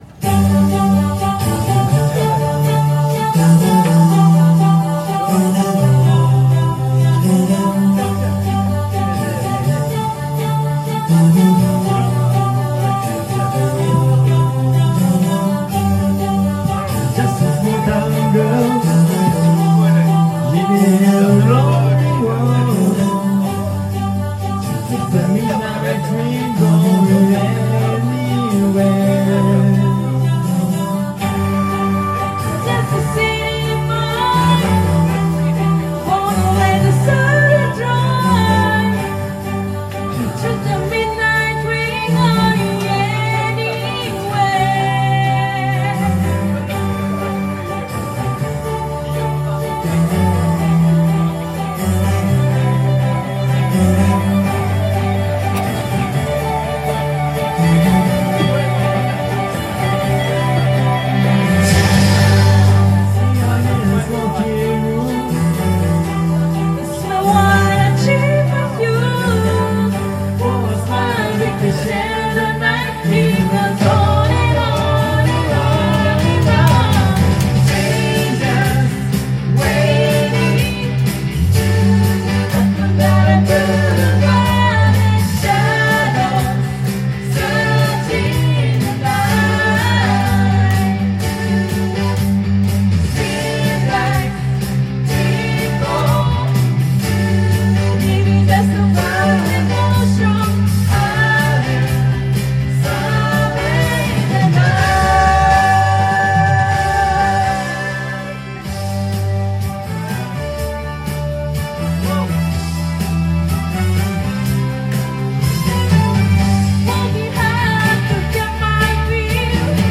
Duet & Chorus Night Vol. 21 TURN TABLE